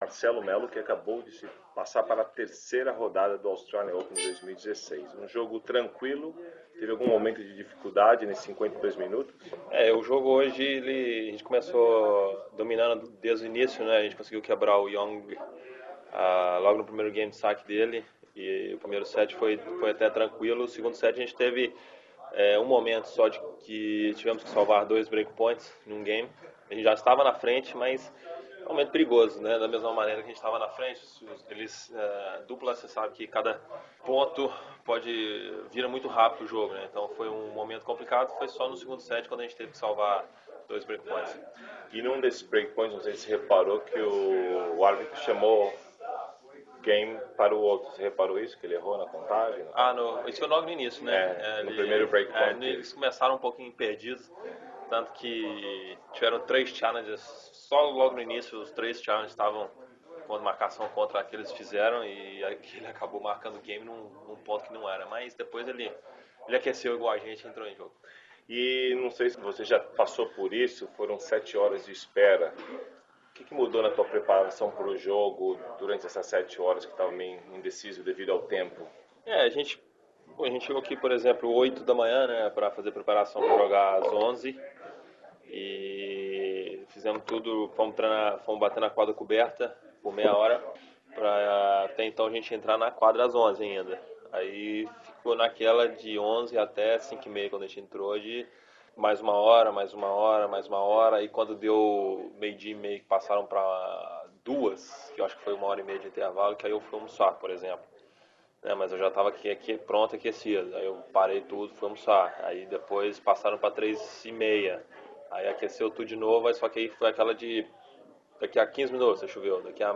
O brasileiro Marcelo Melo, de 32 anos, passou para a terceira rodada do Australian Open e fala nesta entrevista que somente agora caiu a ficha do seu feito de ser o número 1 do mundo em duplas.